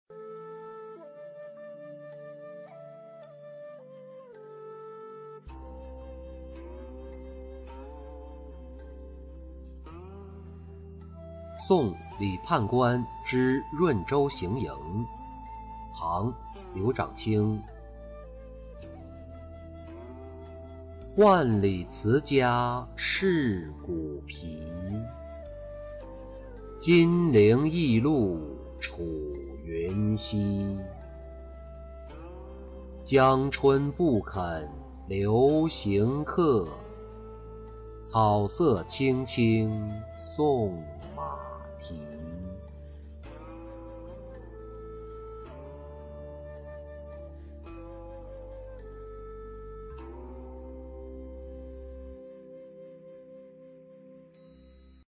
送李判官之润州行营-音频朗读